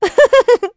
Estelle-Vox_Happy2.wav